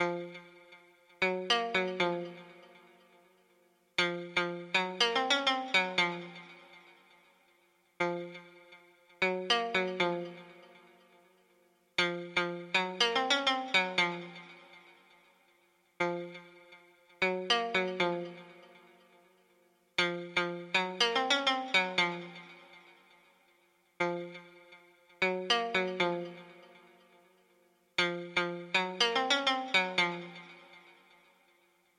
退出舞台东弦乐弹奏120bpm16bars
描述：用我的Novation Ultranova合成器制作的循环。
这些循环具有亚洲风味，可用于各种电子甚至HipHop类型。
Tag: 120 bpm Electronic Loops Strings Loops 2.69 MB wav Key : Unknown